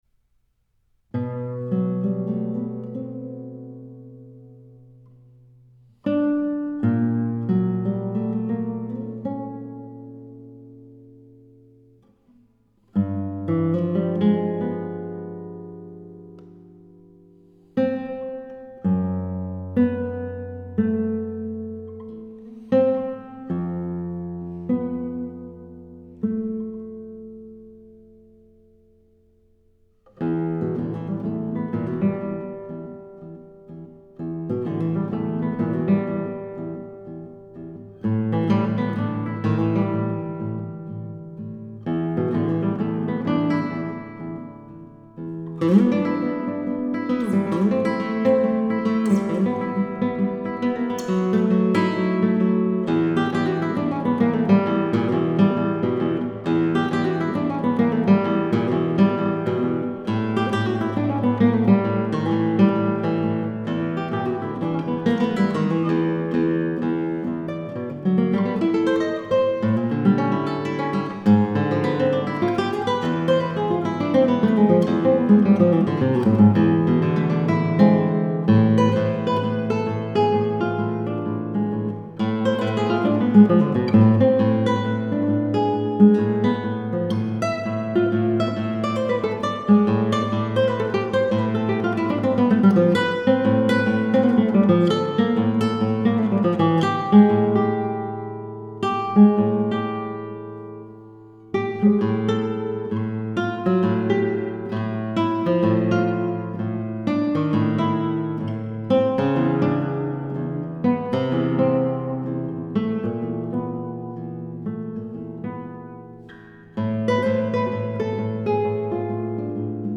a 6 movement concert suite.